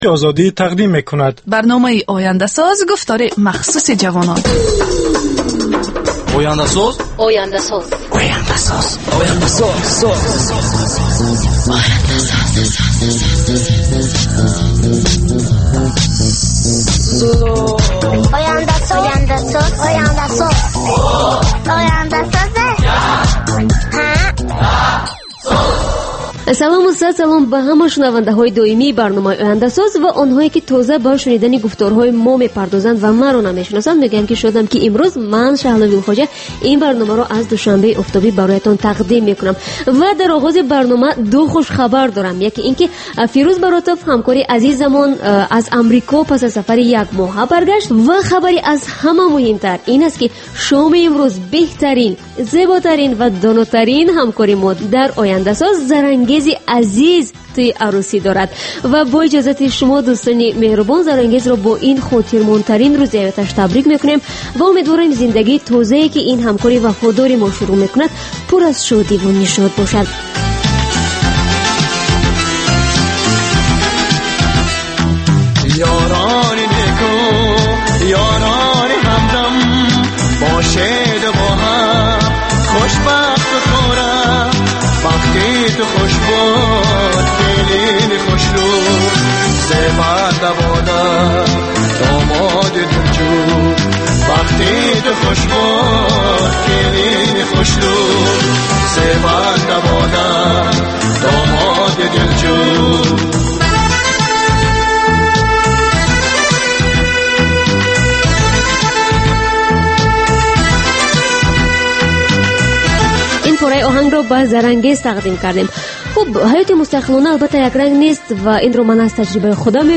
Бар илова, дар ин гуфтор таронаҳои ҷаззоб ва мусоҳибаҳои ҳунармандон тақдим мешавад.